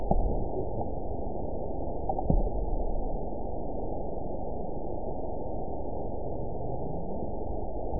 event 912259 date 03/22/22 time 15:07:22 GMT (3 years, 7 months ago) score 9.51 location TSS-AB05 detected by nrw target species NRW annotations +NRW Spectrogram: Frequency (kHz) vs. Time (s) audio not available .wav